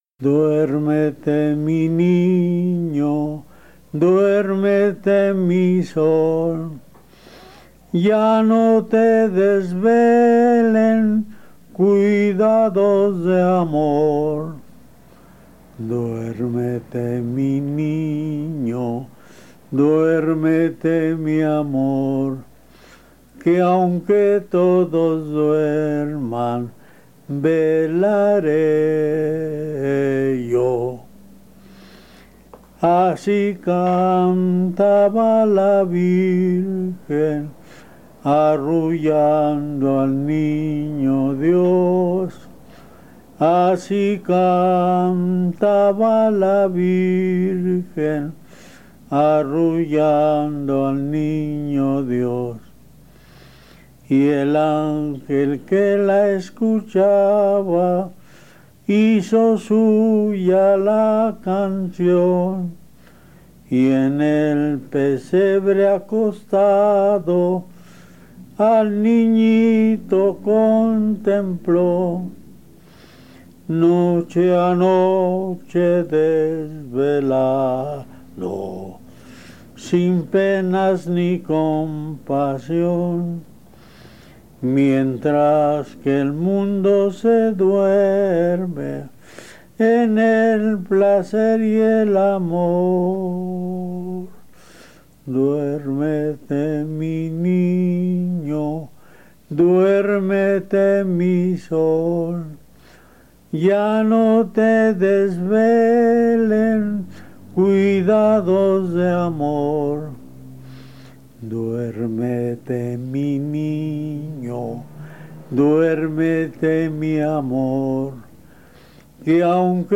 Keywords: música de navidad
Grabaciones de campo
arrullo